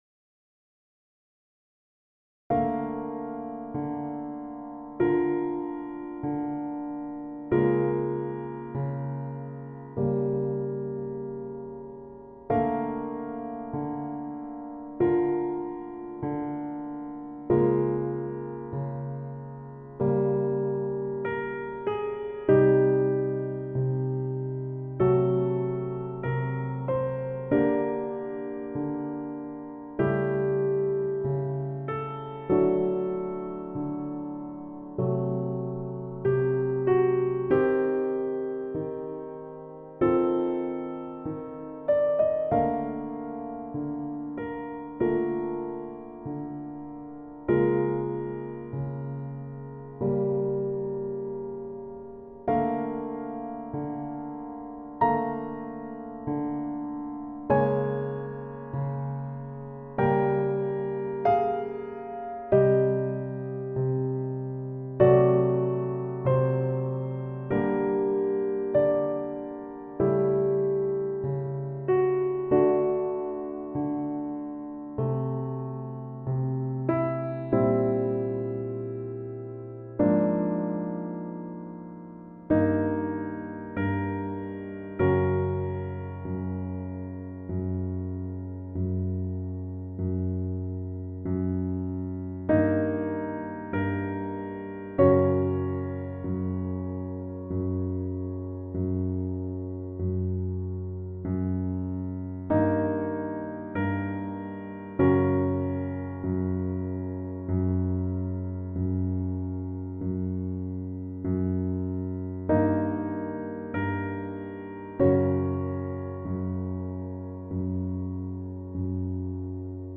たった1分の短いピースを
時にシンプルで 時に複雑なひびき 重なりあう音色の変化 ジャジーなテンション 人間らしいアナログな間